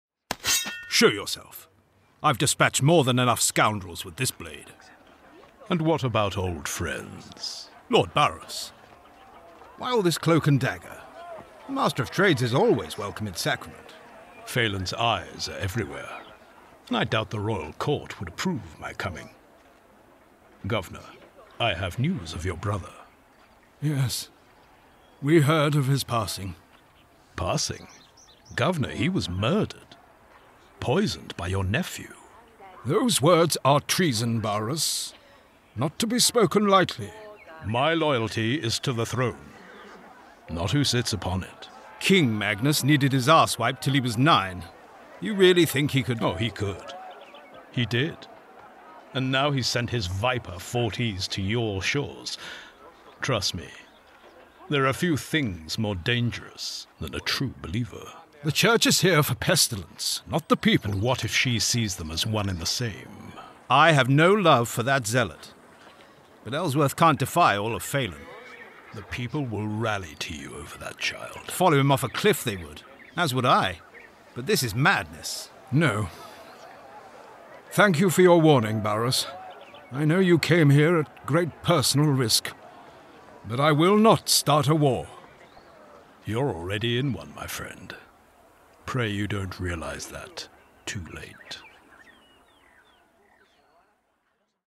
English (British)
Playful, Versatile, Reliable, Warm, Corporate
Corporate